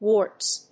発音記号・読み方
/wɔrts(米国英語), wɔ:rts(英国英語)/